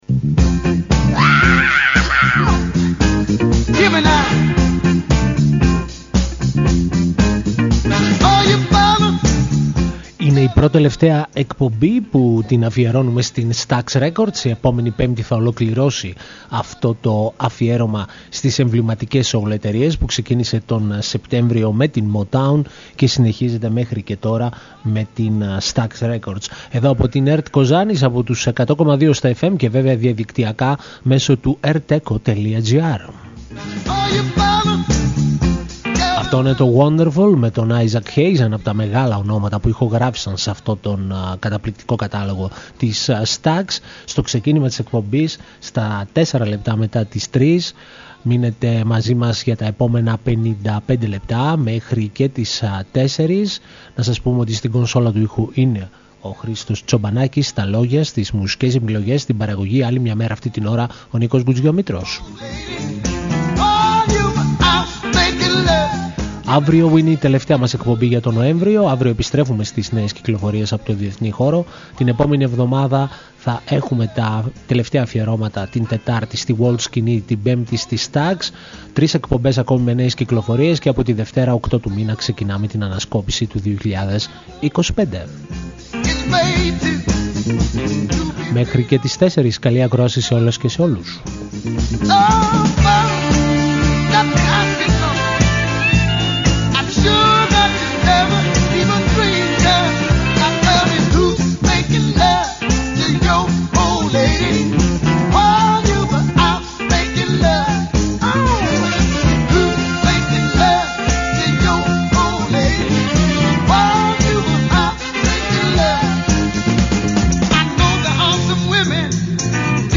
soul μουσική